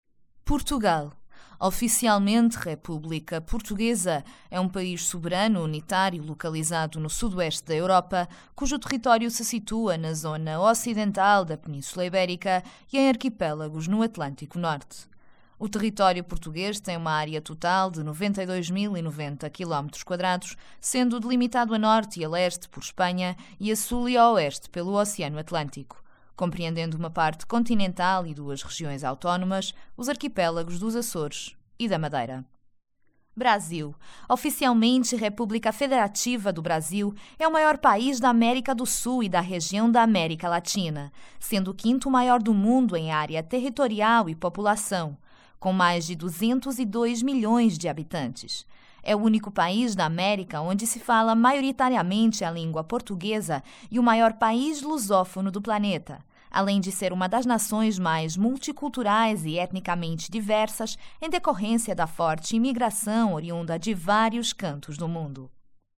✔ My guarantee: I have my own home studio.
Sprechprobe: Werbung (Muttersprache):